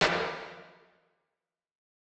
CLAP - GUILTY PLEASURE.wav